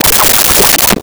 Swirl 02
Swirl 02.wav